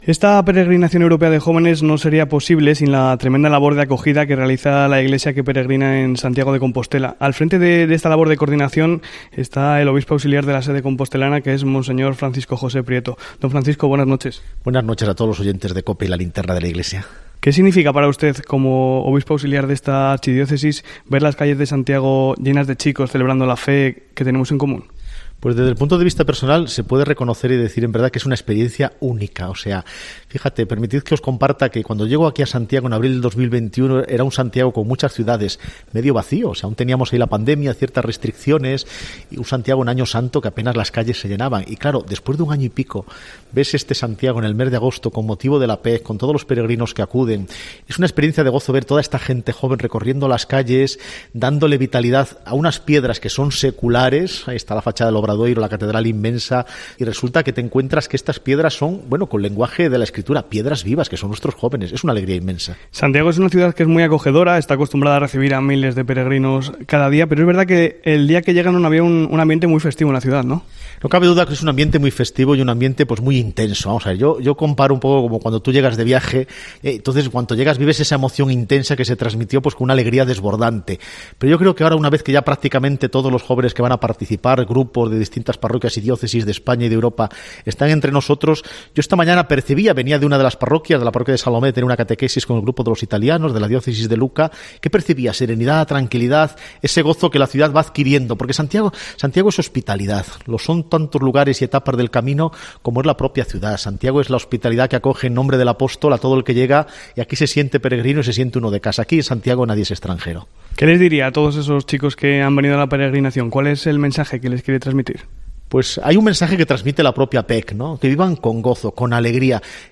AUDIO: El obispo auxiliar de Compostela ha estado en el programa especial de la PEJ de 'La Linterna de la Iglesia'